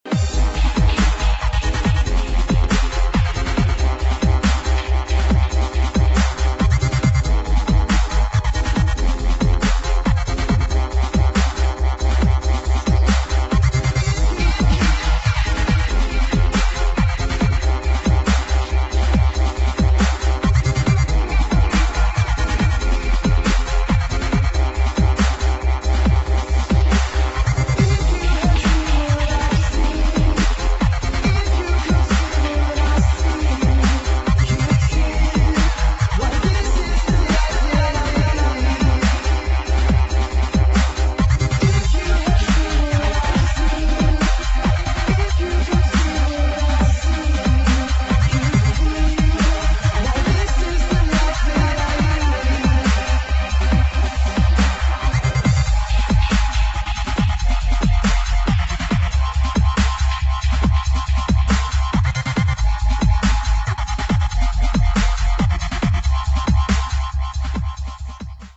[ DUBSTEP / GRIME ]